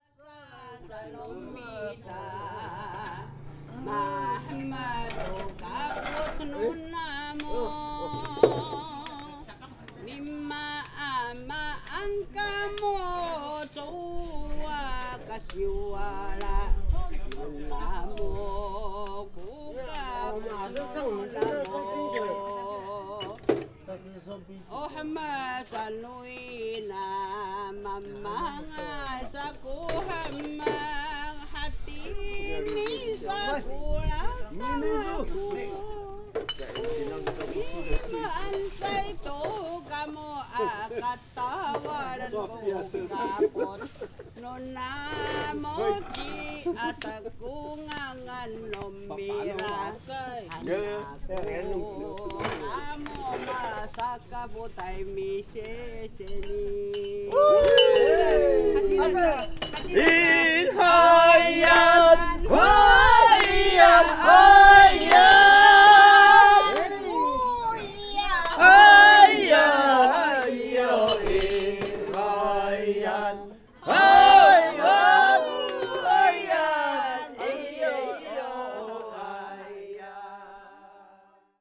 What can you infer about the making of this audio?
singing and play at the pig killing banquet. 2014 october.